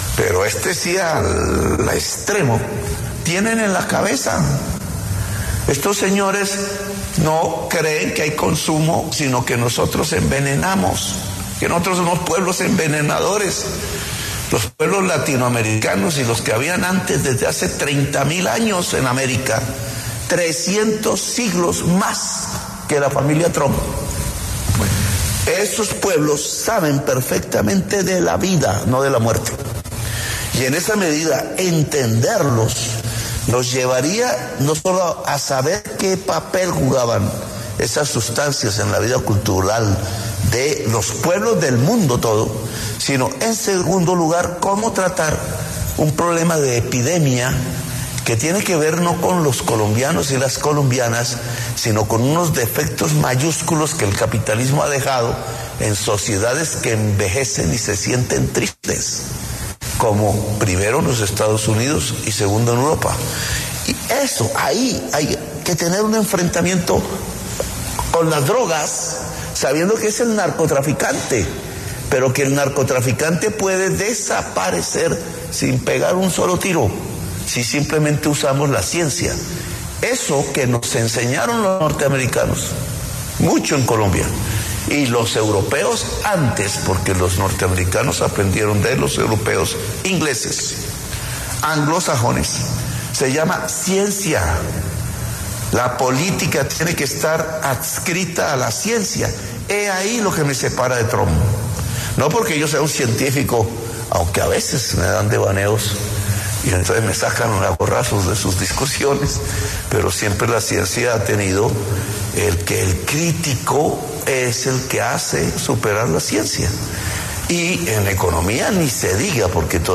“Dijo Trump que Colombia está fuera de control, pues claro que está fuera de control de él”, fue parte de la respuesta del presidente Petro en conversación con Daniel Coronell.
Gustavo Petro, presidente de la República, dialogó con Daniel Coronell acerca de la tensión diplomática con Estados Unidos y su mandatario, Donald Trump, quien lo calificó como “líder del narcotráfico” y mencionó que Colombia está “fuera de control”.